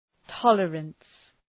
Προφορά
{‘tɒlərəns}